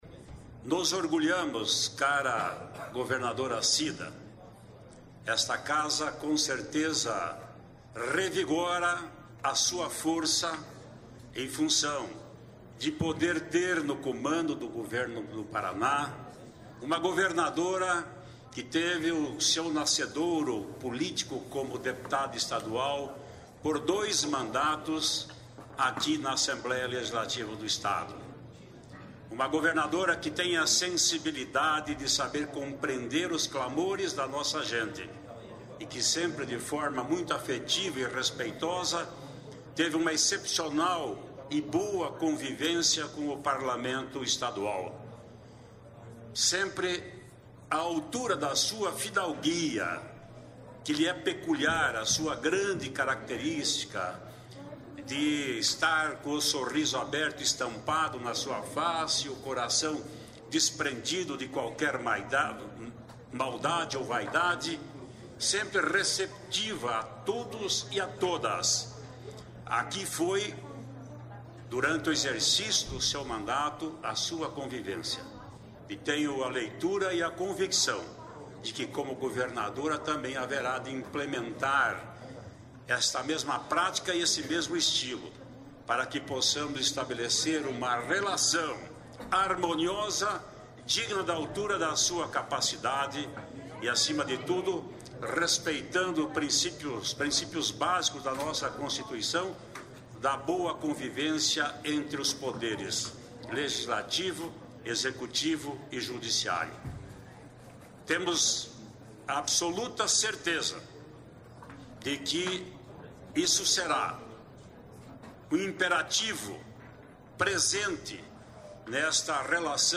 Ouça o discurso do rpesidente da Assemblkeia legislativa, deputado Ademar Traiano (PSDB), na cerimônia de posse da governadora Cida Borghetti (PP).